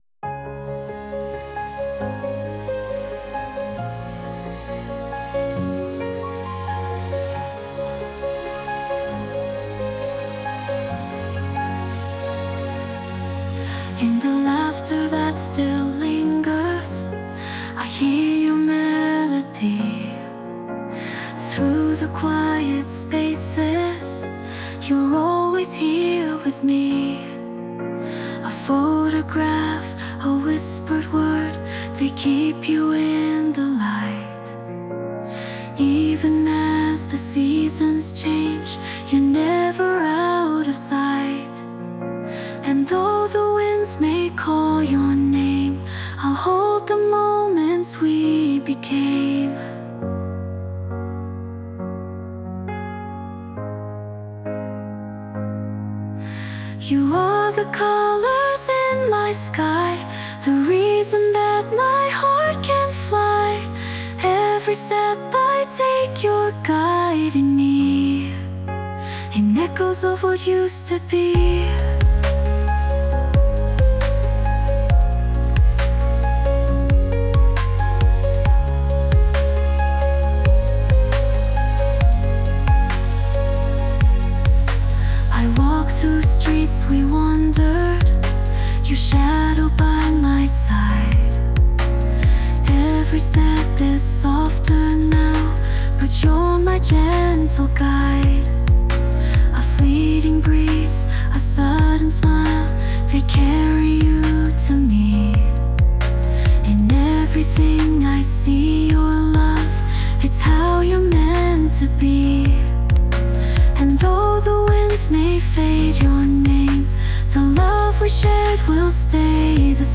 女性ボーカル洋楽 女性ボーカル
著作権フリーオリジナルBGMです。
女性ボーカル（洋楽・英語）曲です。